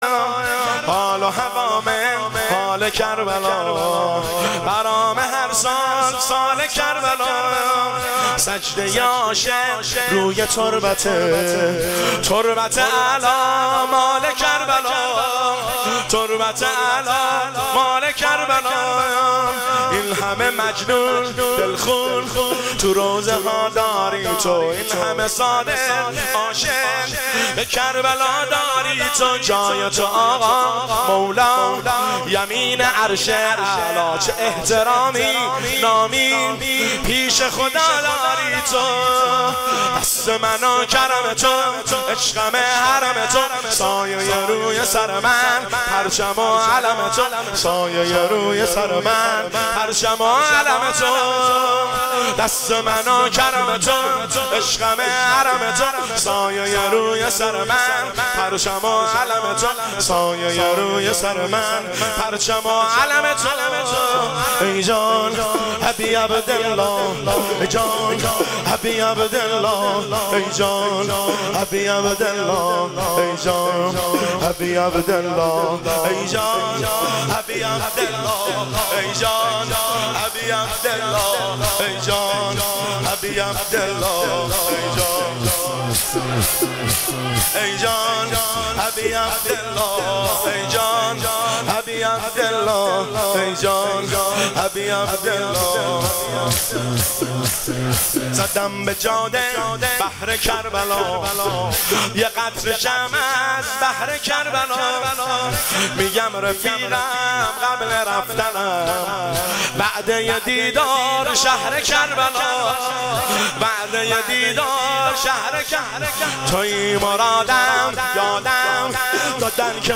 شور شب دوم فاطمیه دوم 1404